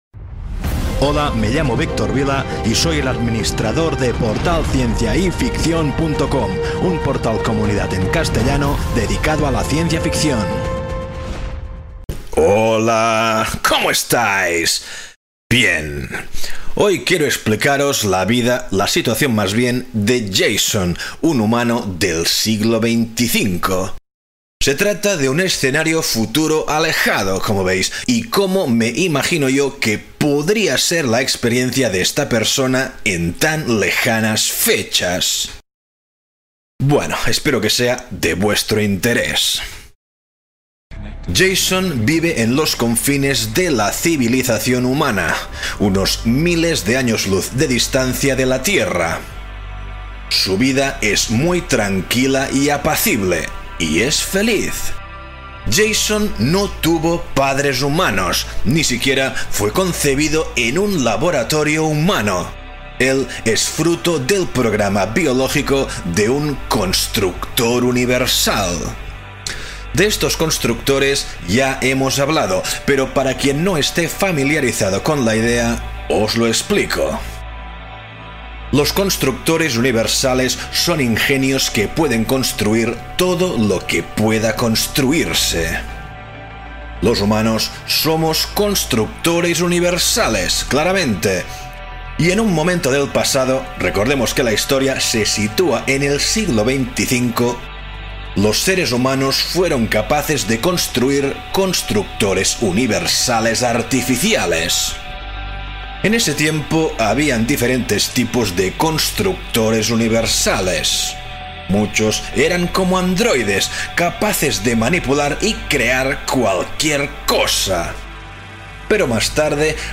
Una idea sobre un futuro lejano que me rondaba por la cabeza, que pasé a audiorelato.